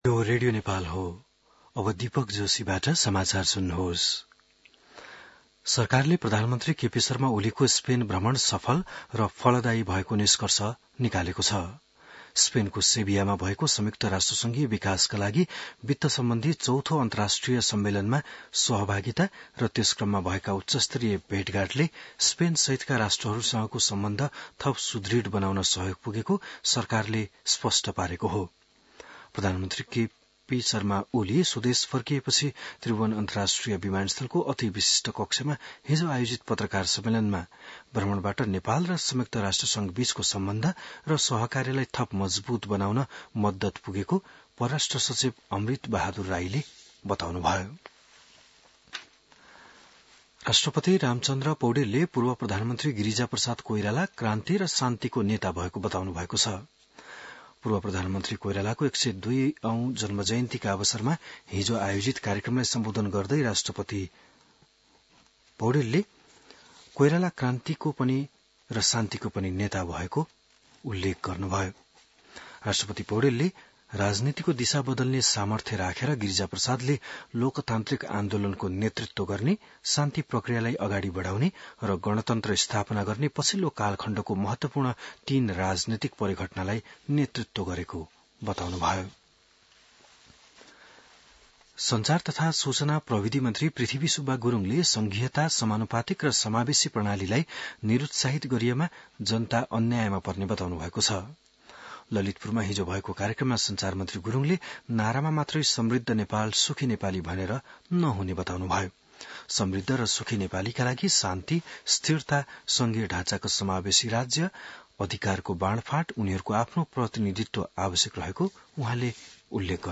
बिहान १० बजेको नेपाली समाचार : २१ असार , २०८२